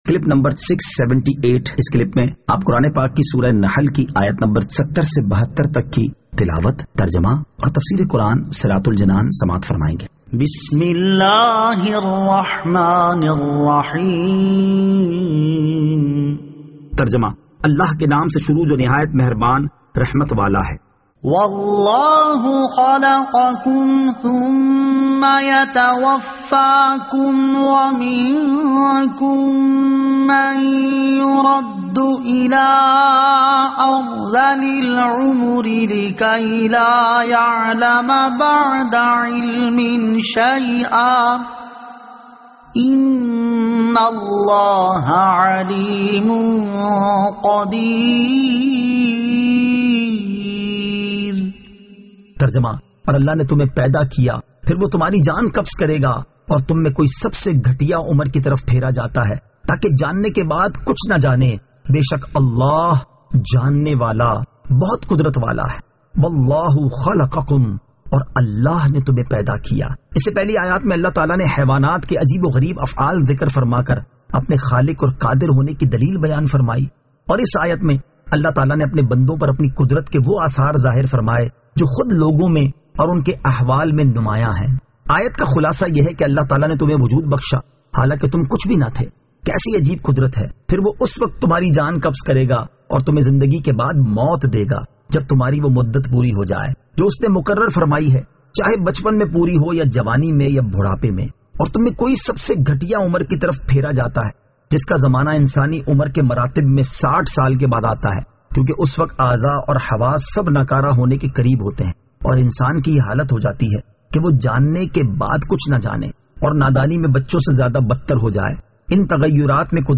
Surah An-Nahl Ayat 70 To 72 Tilawat , Tarjama , Tafseer
2021 MP3 MP4 MP4 Share سُوَّرۃُ النَّحٗل 70 تا 72 تلاوت ، ترجمہ ، تفسیر ۔